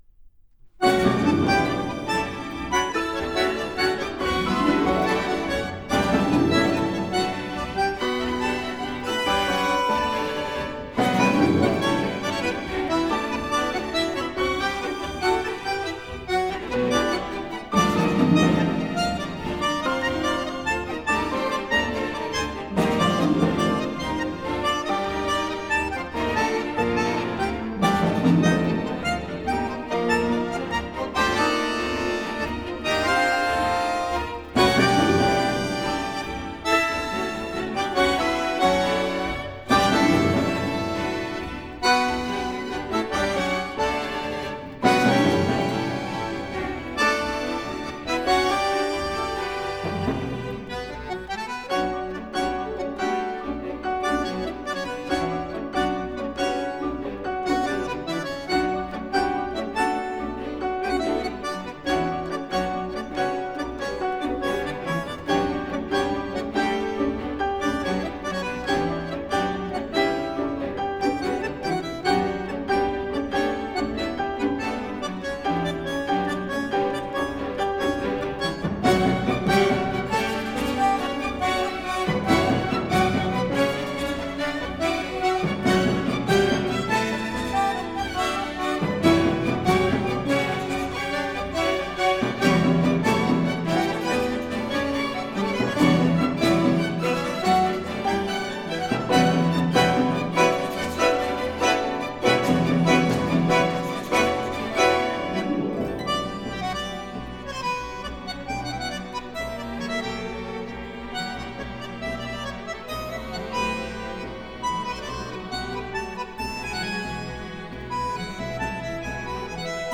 Genre: Tango
Concerto for Bandoneón, String Instruments and Percussion
Recorded at MCO Hilversum, The Netherlands, April 2002.